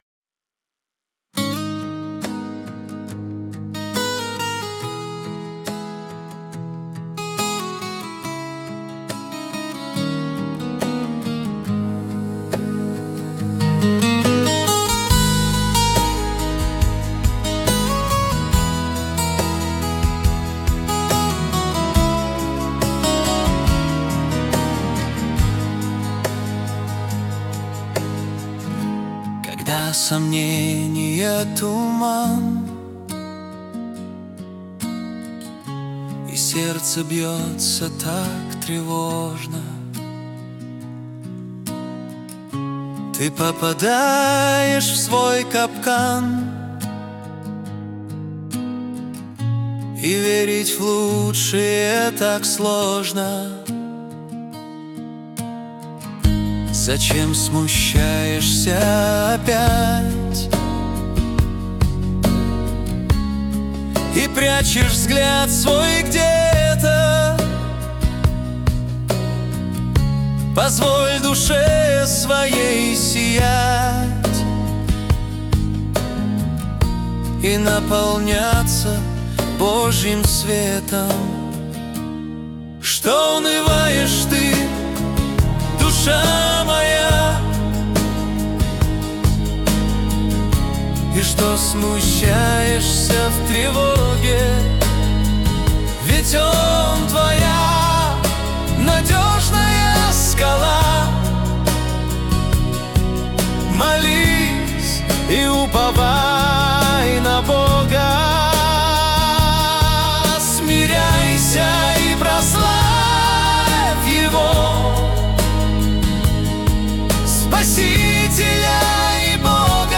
492 просмотра 1321 прослушиваний 159 скачиваний BPM: 70